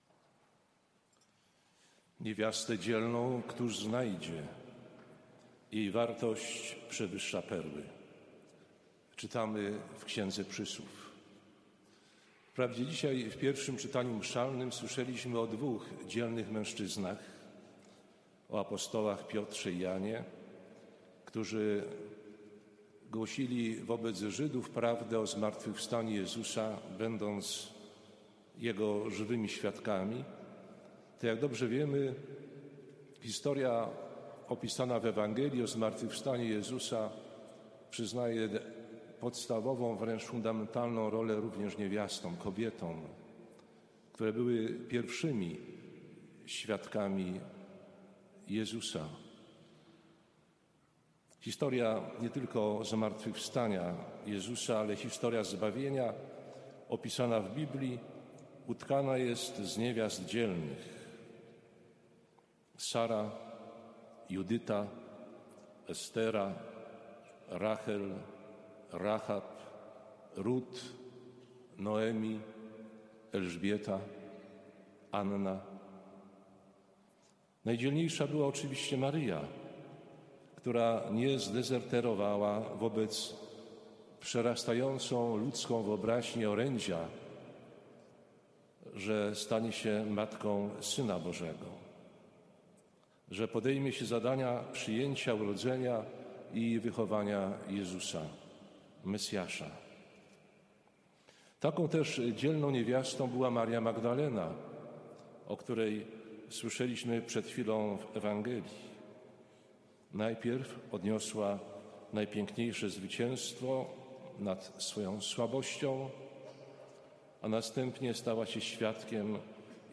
W sobotę, 23 kwietnia, w Świątyni Opatrzności Bożej odbyły się uroczystości pogrzebowe śp. Karoliny Kaczorowskiej, której prochy spoczęły w Panteonie Wielkich Polaków obok jej męża, Ryszarda Kaczorowskiego, ostatniego Prezydenta Rzeczypospolitej na Uchodźstwie.
Homilia-biskupa-Lechowicza-podczas-Mszy-sw.-pogrzebowej-Karoliny-Kaczorowskiej.mp3